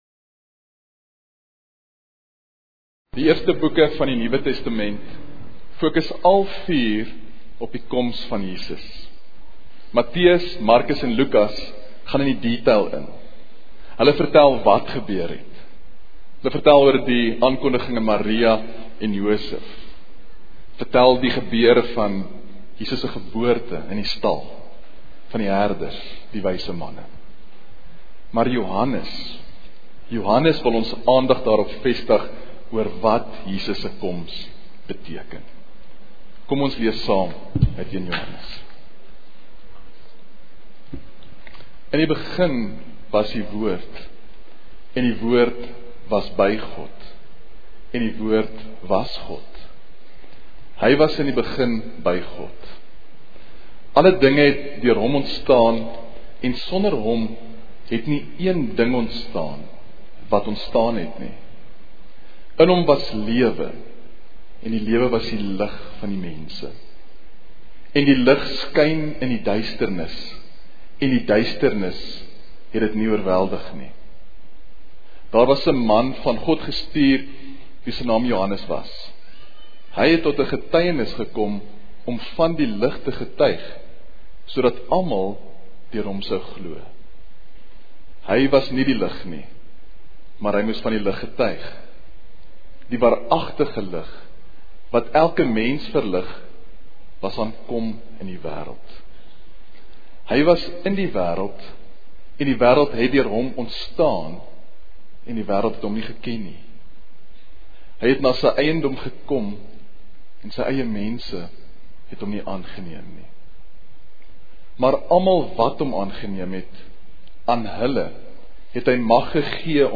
Prediker